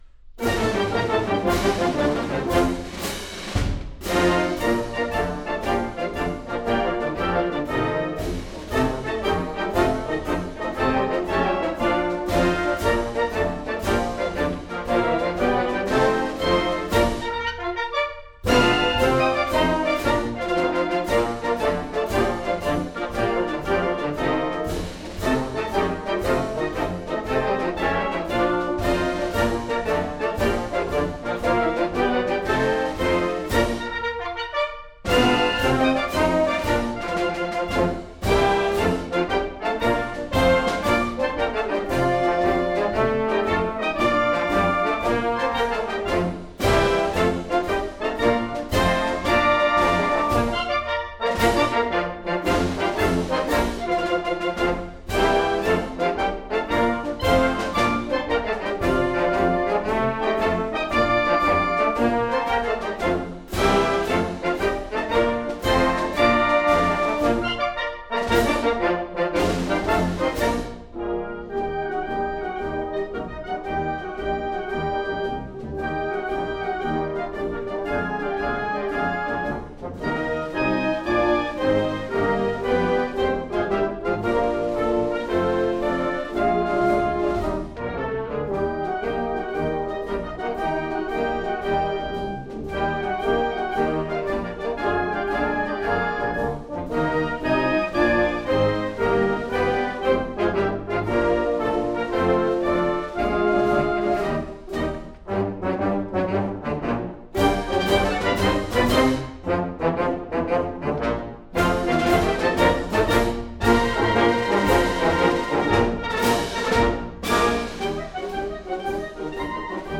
Db Piccolo
C Flute
Bassoon
Bb Clarinet
Alto Saxophone
Bb Cornet
Trombone
Euphonium
Tuba
using a pair of Oktava MK-12 omnidirectional